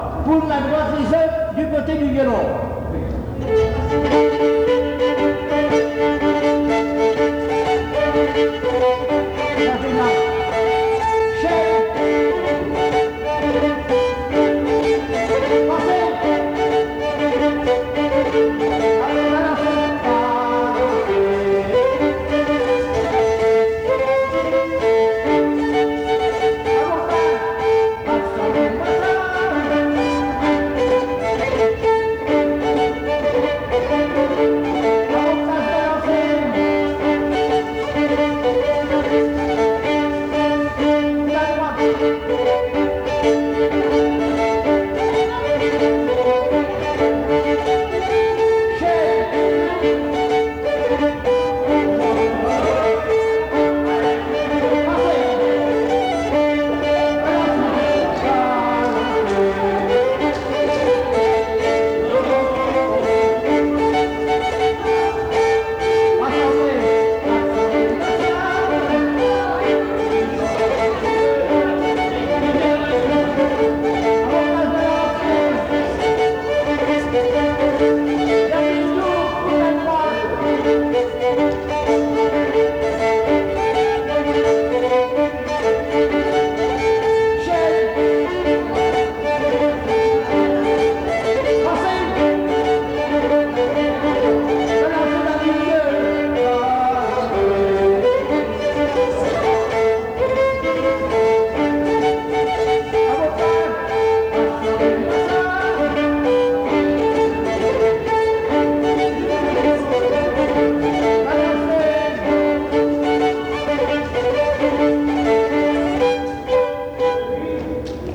danse : quadrille : poule
Pièce musicale inédite